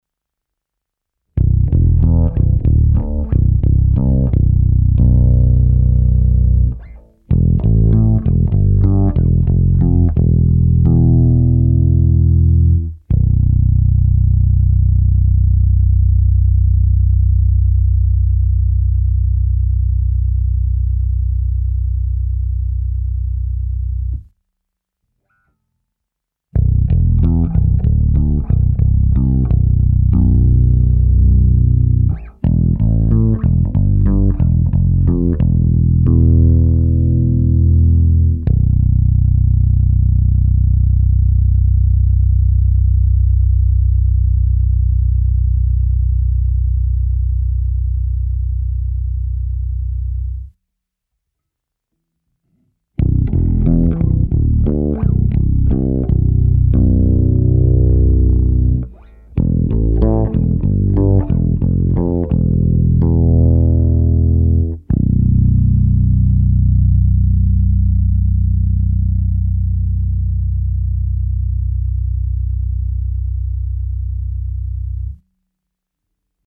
To samé se simulací aparátu